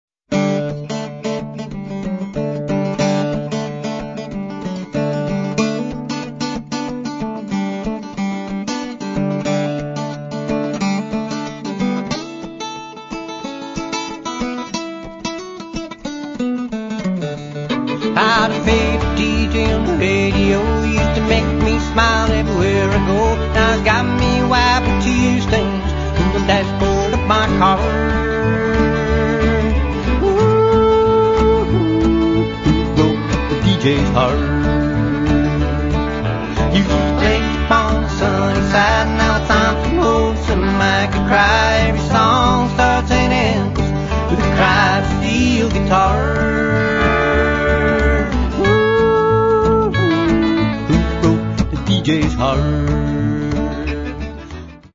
It’s music which sounds warm, sympathetic, and gentle.
We’re talking about blue-grass, country, folk
acoustic guitar